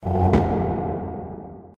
incomingMessage.wav